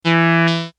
Free MP3 vintage Korg PS3100 loops & sound effects 8